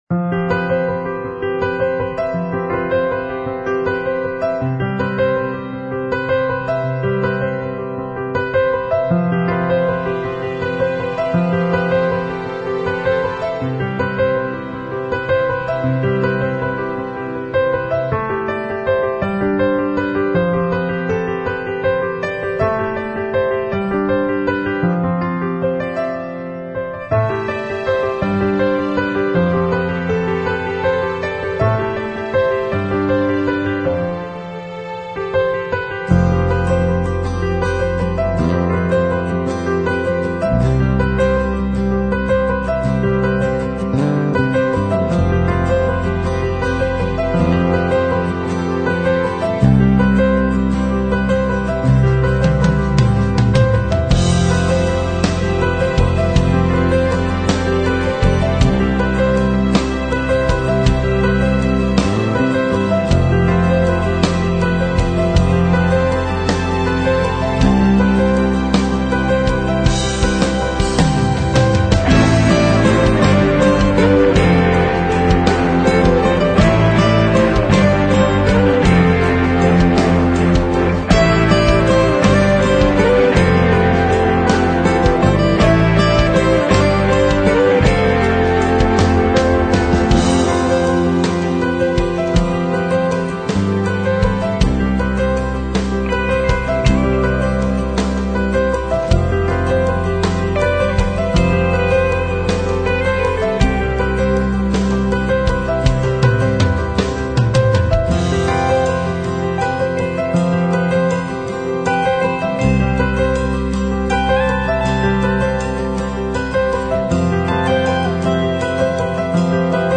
用不断增加的旋律说明进步和发现。
下划线的建立和减速，将带给您的听众一个希望的旅程，以发现无数的奖励。
采样率16位立体声，44.1 kHz
节奏（BPM）160